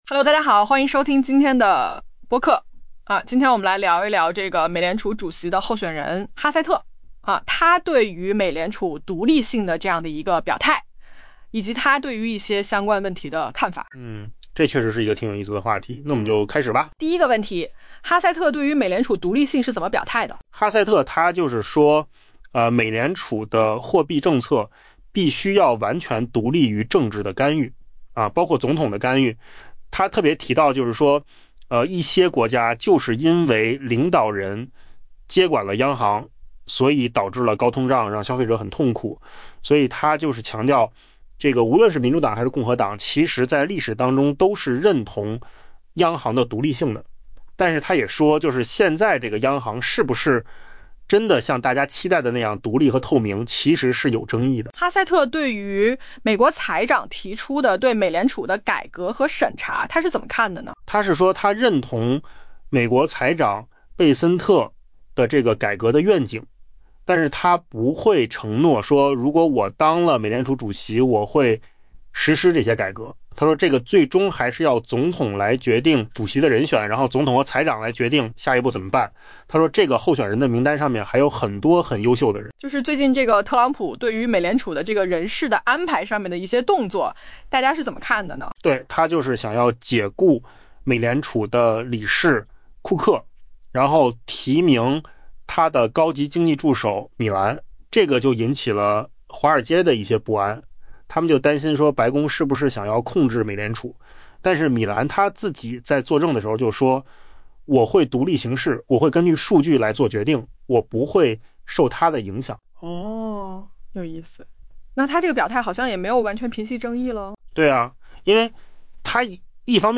AI 播客：换个方式听新闻 下载 mp3 音频由扣子空间生成 美国国家经济委员会主任凯文·哈塞特 （Kevin Hassett） 上周日表示， 美联储的货币政策 「需要完全独立于政治影响——包括特朗普总统的影响」 。